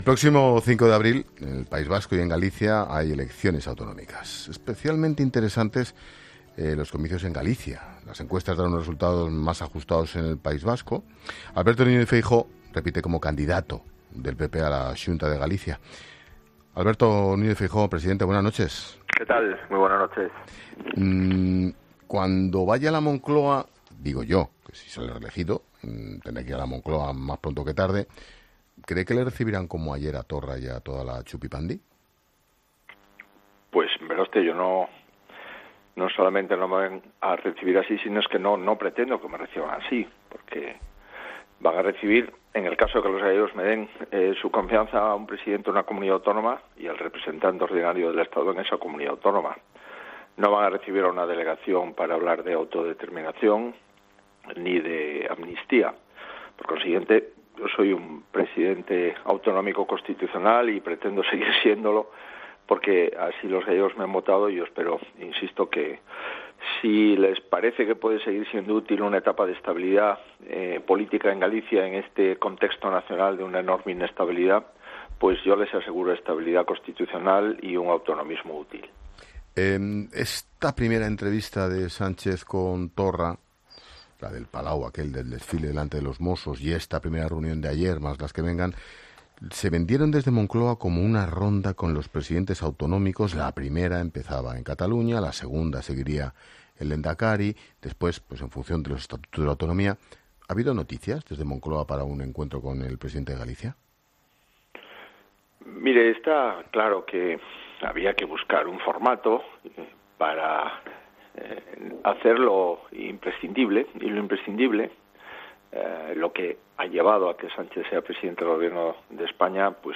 El presidente de la Xunta analiza la actualidad política en los micrófonos de 'La Linterna' con Ángel Expósito